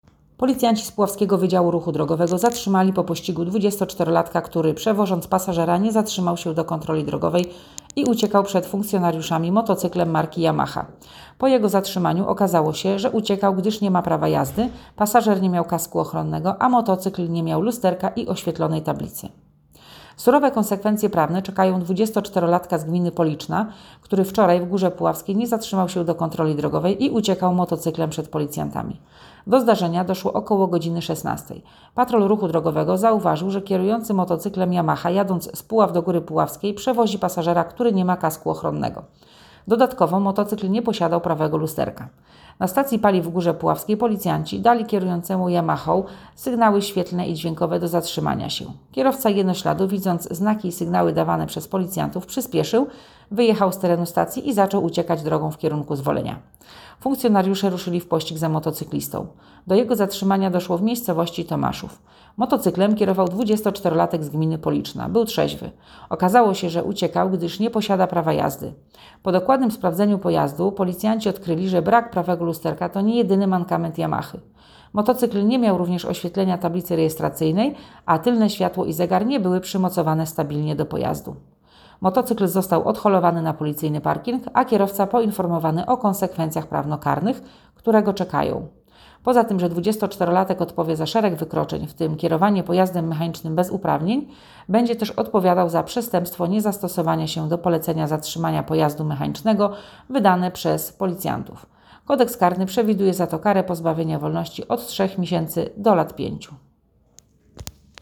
Nagranie audio Nagranie_komunikatu_nie_zatrzymal_sie_motocyklem_do_kontroli_drogowej.m4a